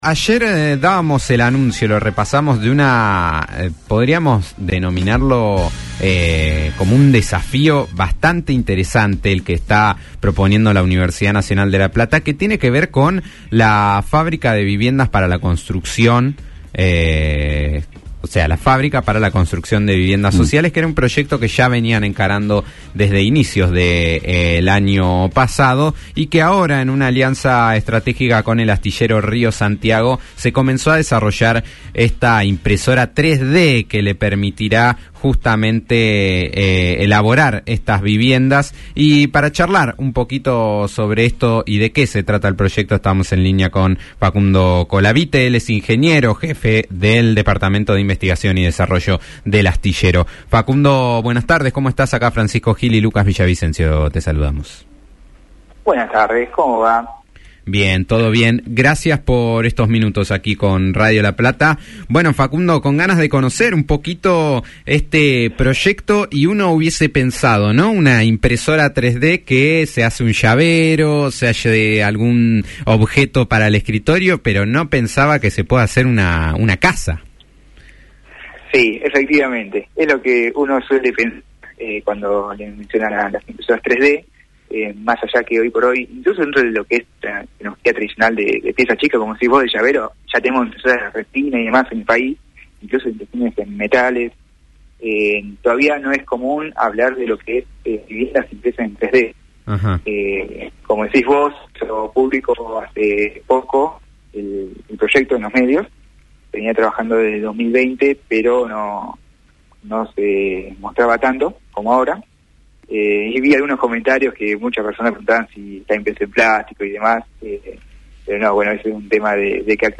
en diálogo con Después del Mediodía (fm 90.9)